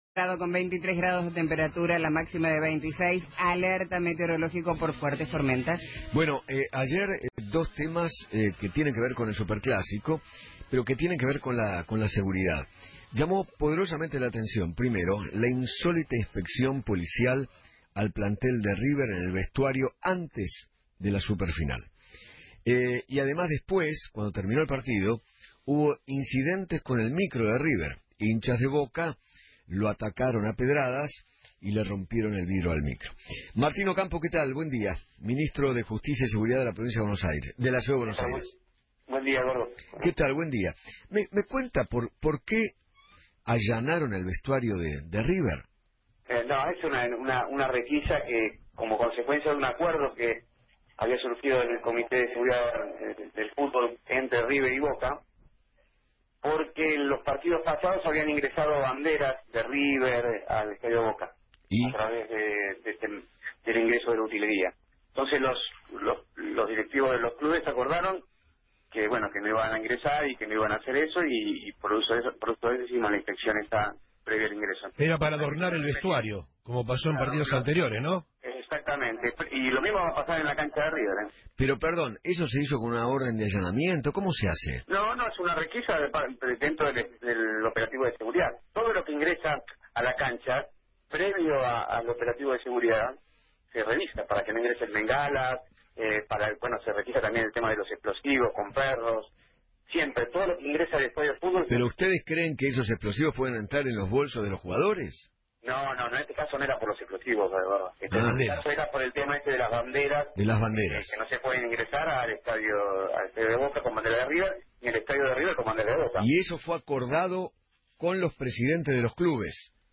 Martín Ocampo, Ministro de Seguridad y Justicia de la Ciudad de Buenos Aires, habló en Feinmann 910 y contó que  “La inspección se hizo por un acuerdo que surgió entre River Plate y Boca Juniors, porque la vez pasada ingresaron banderas de River el vestuario. Lo mismo pasará en la cancha de River Plate con Boca Juniors.”